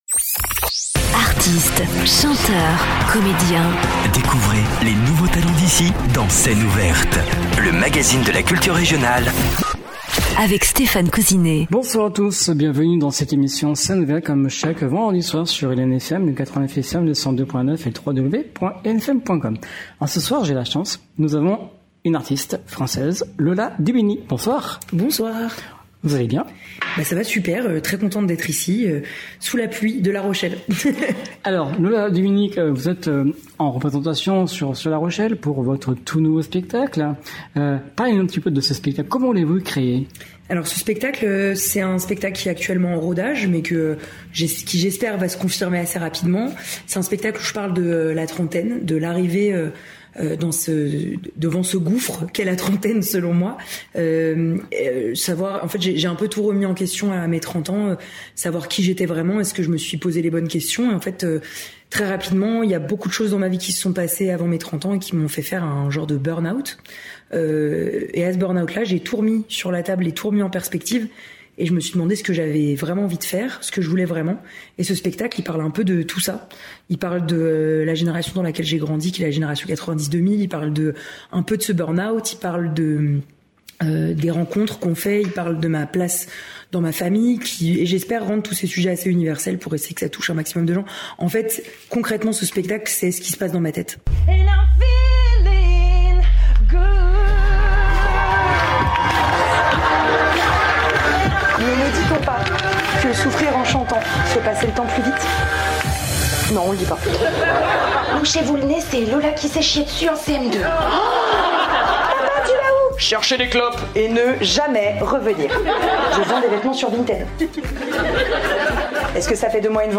Scène ouverte du 13/02/2026 avec la comédienne et chanteuse Lola Dubini.